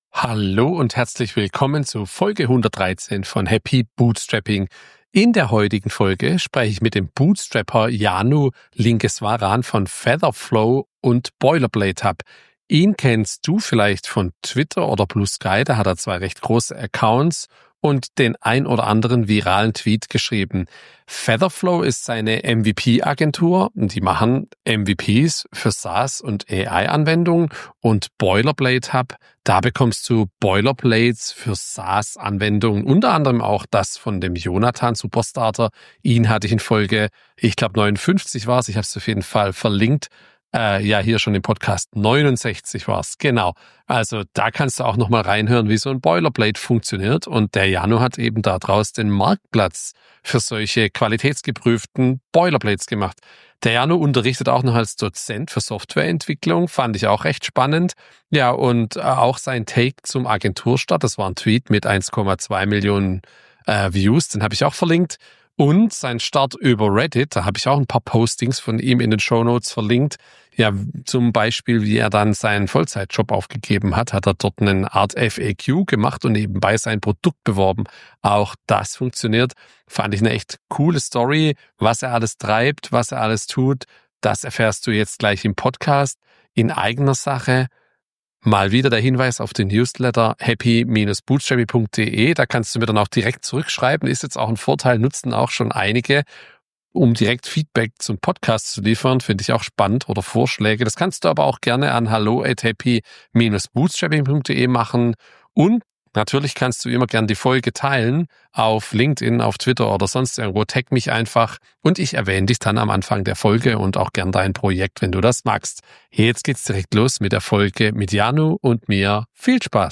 Bei "Happy Bootstrapping" spreche ich mit Gründer:innen über Höhen und Tiefen des Bootstrapping und die Besonderheiten dieser Form der Unternehmensgründung.